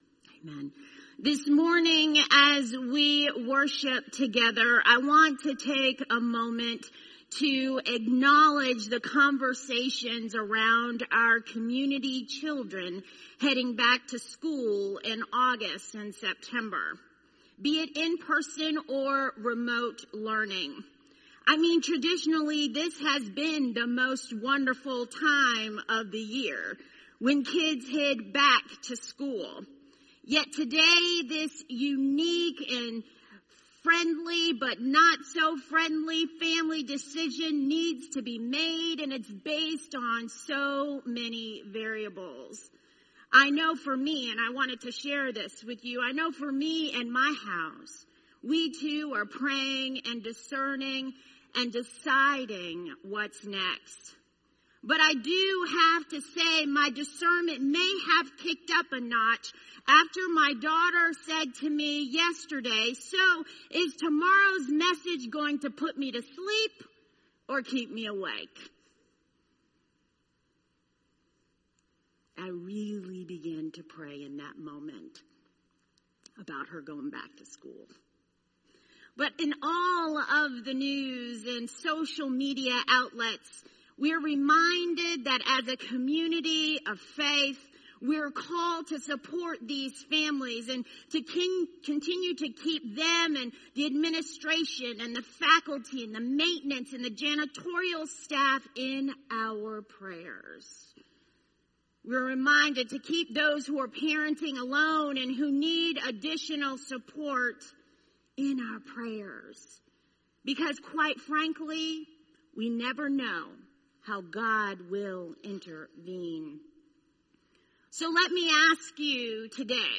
sermon-726.mp3